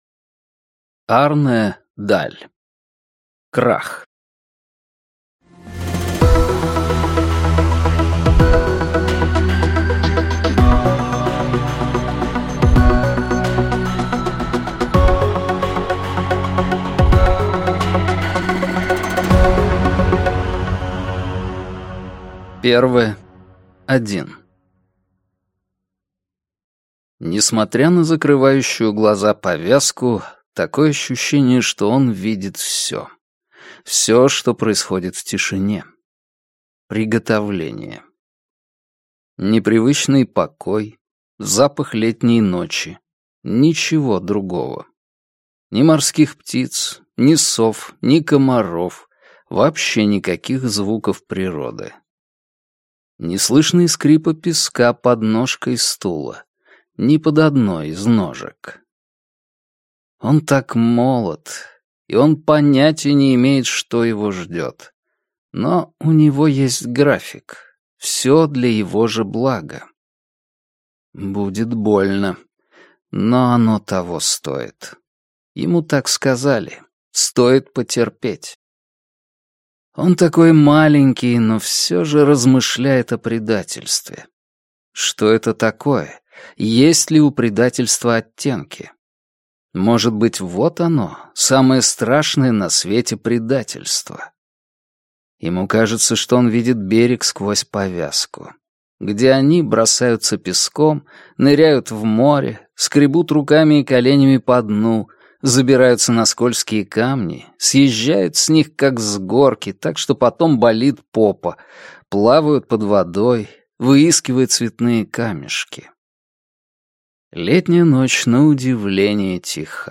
Аудиокнига Крах | Библиотека аудиокниг